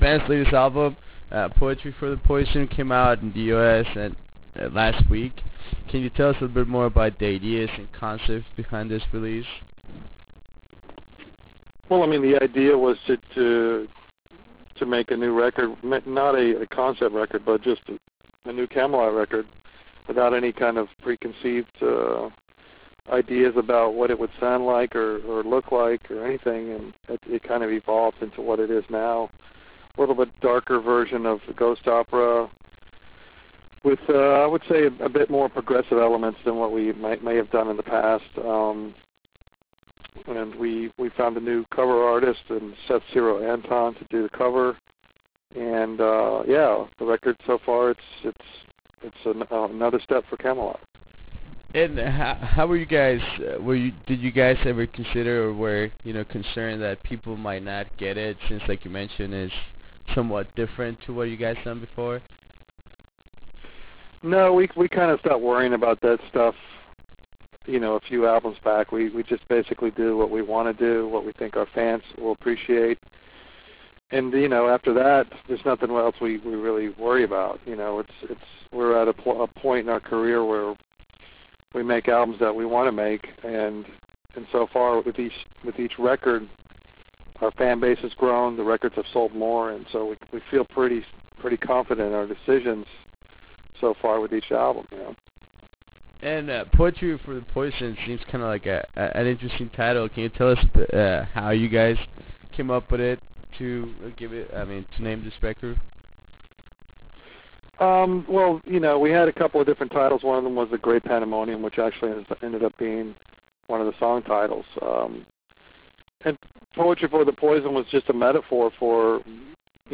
Interview with Kamelot - Thomas Youngblood
We had the opportunity to have a small conversation with one of the band’s founding members and guitar wizard Thomas Youngblood. In this 18 minute interview we talk about the new album and the ideas behind releasing a darker and more progressive album. We also talk about why did they canceled their upcoming USA tour and what is in store for the band in the future.